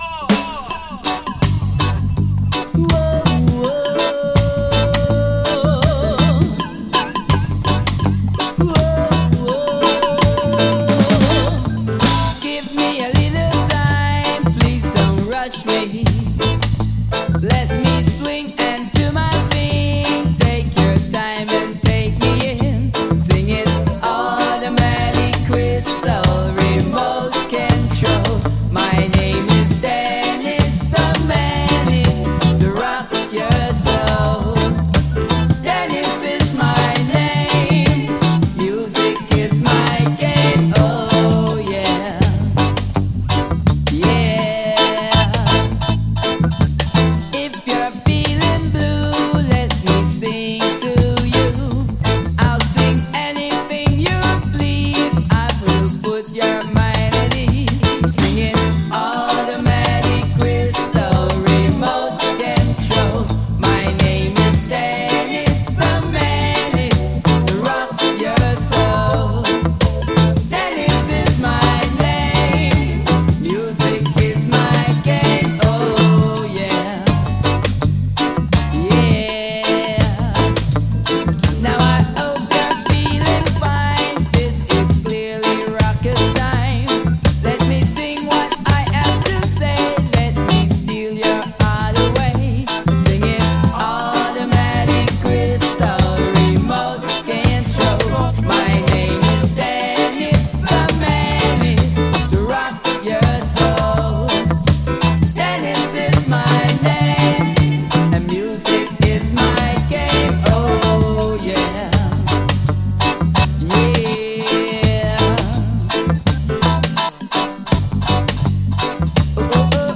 REGGAE
バックのアレンジ、ミックスも小技が効いてます。
こちらも囁くようなコーラス・ハーモニーが素晴らしい！こちらは後半ダブに接続。